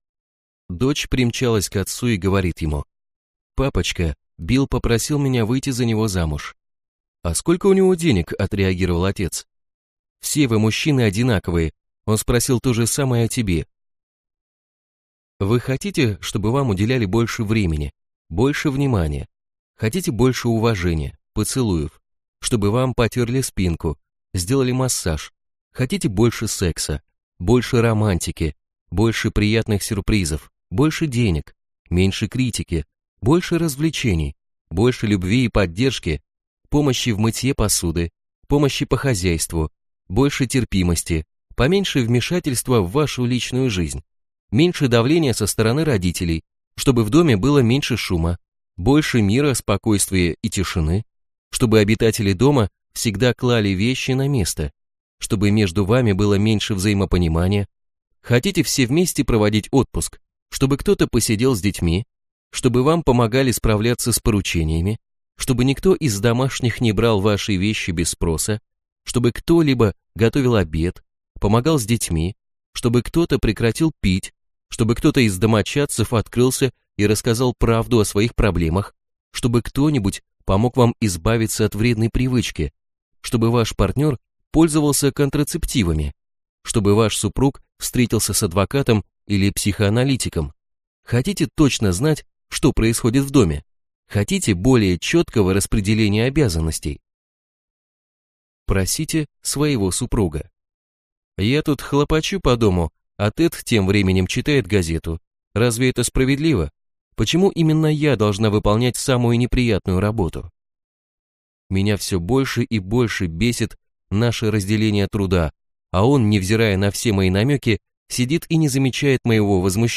Аудиокнига Всё, что душа пожелает, или Фактор Аладдина | Библиотека аудиокниг